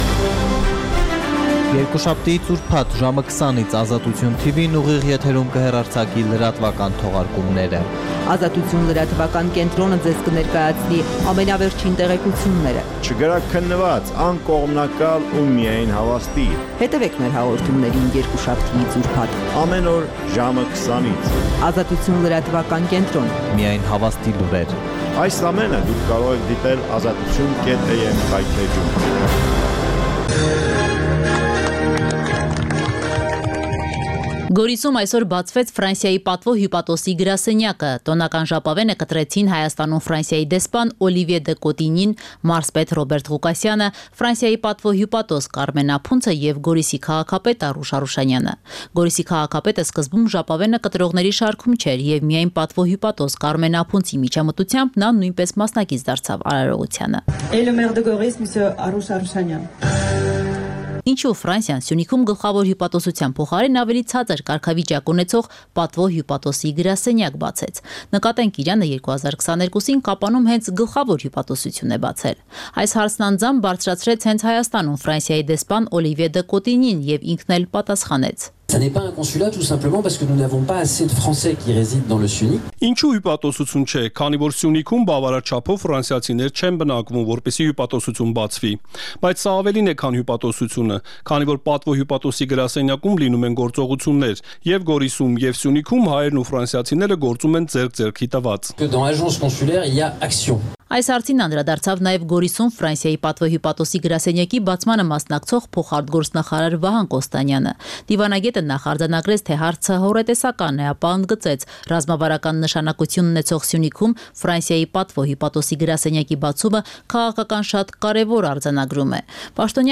Տեղական եւ միջազգային լուրեր, ռեպորտաժներ, հարցազրույցներ, տեղեկատվություն օրվա սպասվող իրադարձությունների մասին, մամուլի համառոտ տեսություն: